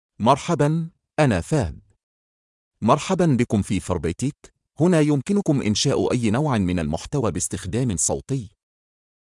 Fahed — Male Arabic (Kuwait) AI Voice | TTS, Voice Cloning & Video | Verbatik AI
MaleArabic (Kuwait)
Fahed is a male AI voice for Arabic (Kuwait).
Voice sample
Listen to Fahed's male Arabic voice.
Male
Fahed delivers clear pronunciation with authentic Kuwait Arabic intonation, making your content sound professionally produced.